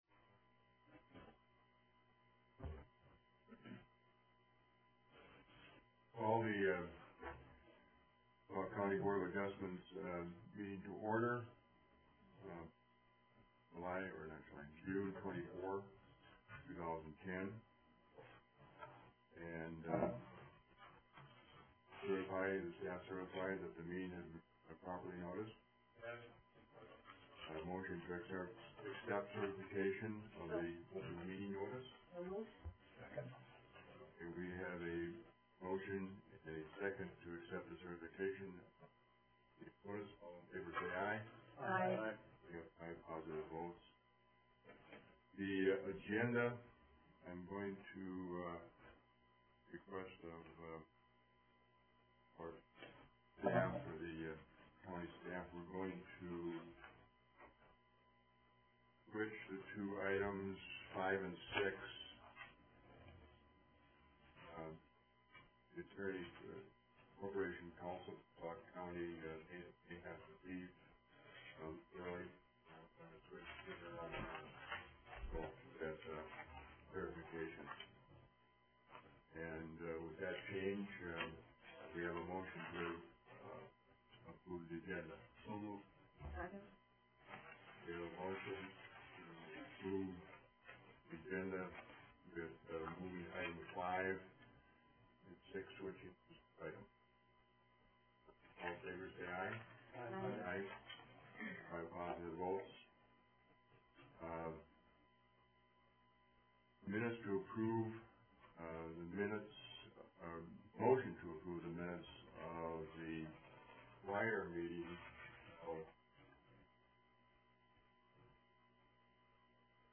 Board of Adjustment public hearing beginning at 9:00 a.m. D.L. Gasser Construction, (SP-13-10), a special exception permit to authorize the operation of a hot mix asphalt plant in an existing quarry.